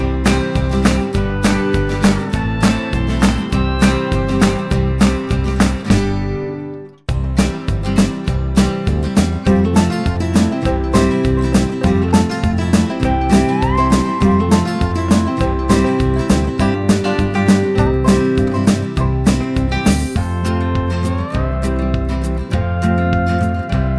no Backing Vocals Country (Female) 3:13 Buy £1.50